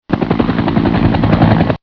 B_HELICO_2.mp3